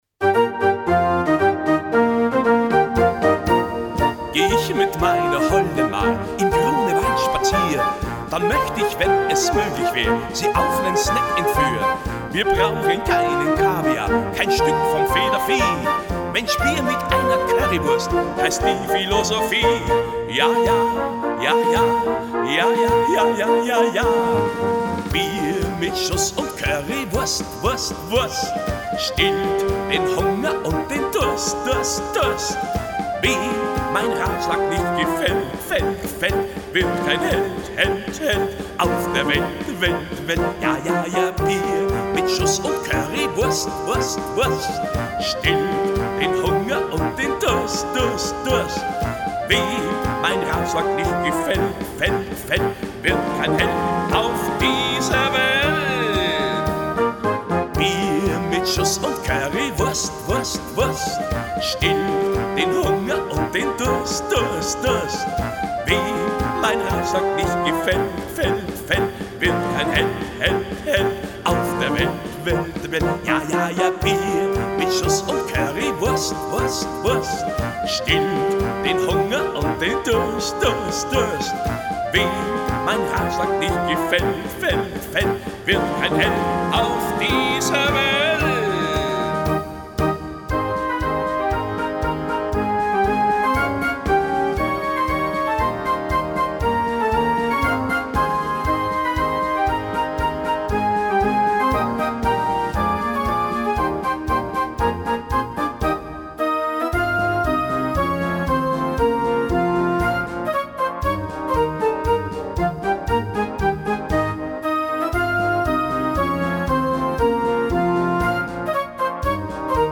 Neuerscheinungen - Blasmusik: